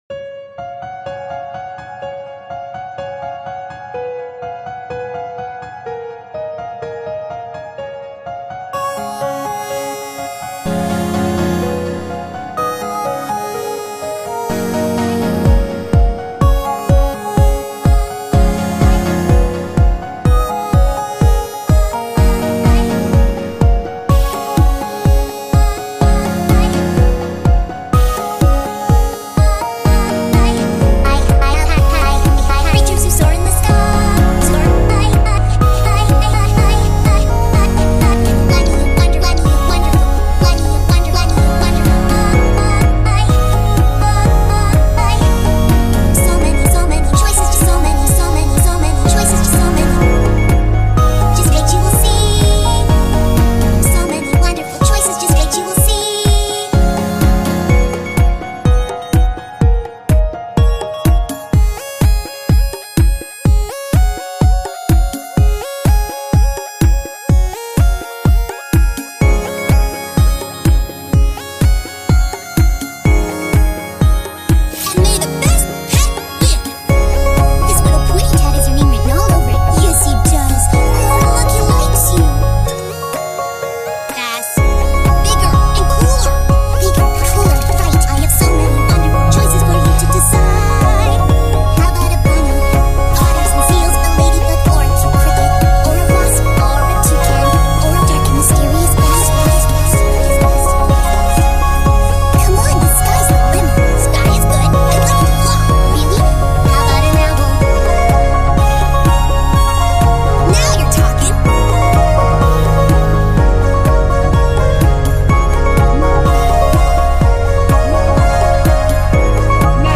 laid back remix
genre:remix